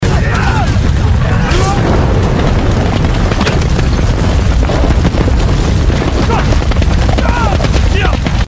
Âm Thanh Đánh Nhau & Vũ Khí – Hiệu Ứng Hành Động Chân Thực
• Âm thanh cận chiến: Tiếng đấm, đá, tiếng ngã và va chạm cơ thể chân thực.
• Tiếng vũ khí lạnh: Tiếng tuốt kiếm, tiếng kim loại va chạm và tiếng vung dao.
• Âm thanh hỏa lực: Tiếng súng nổ, tiếng lên đạn, vỏ đạn rơi và tiếng lựu đạn.